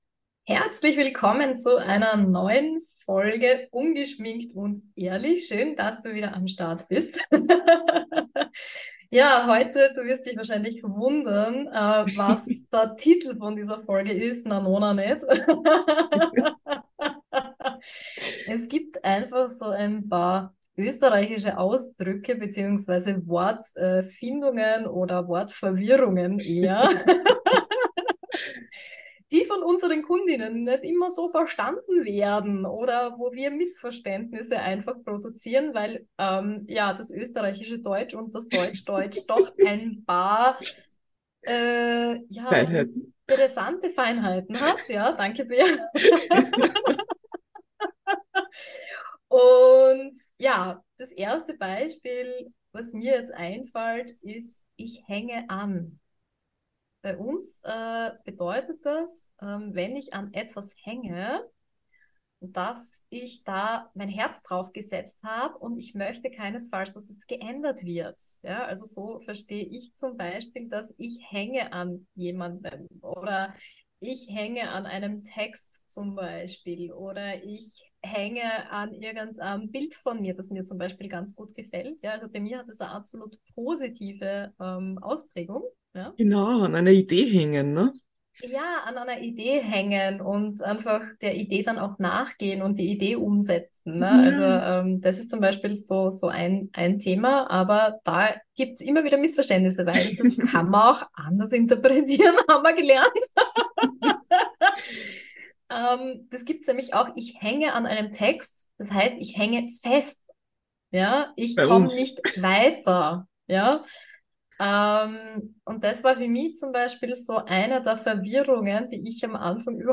In dieser Folge hatten wir besonders Spaß beim Erzählen über Wortverwirrungen mit unseren KundInnen aus Deutschland. Aber auch die Unterschiede mancher Bezeichnungen innerhalb Österreichs haben es in sich. Erlebe, wie zwei Nicht-Trinkerinnen über Wein „fachsimplen“ und selbstverständlich erfährst du auch, was der eigenartige Titel dieser Folge zu bedeuten hat.